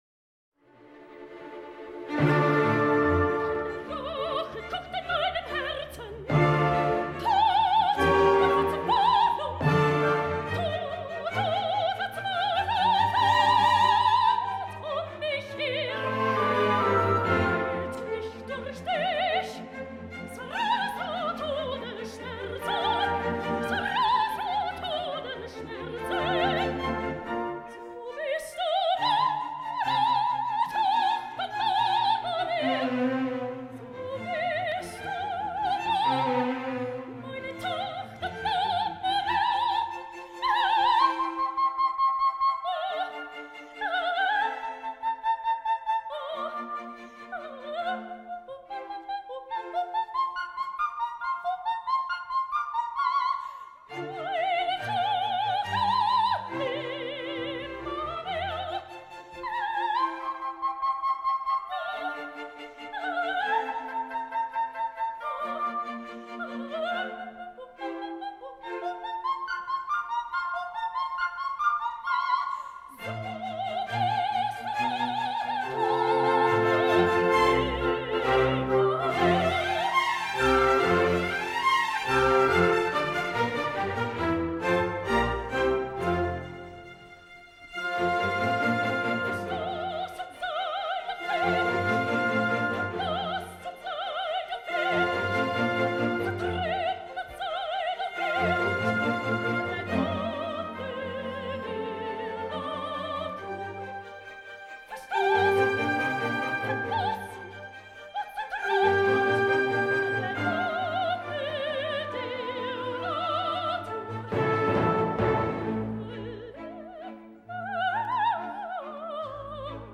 Opera
the-magic-flute-queen-of-the-night-aria-mozart-diana-damrau-the-royal-opera.mp3